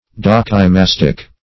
Dokimastic \Dok`i*mas"tic\, a.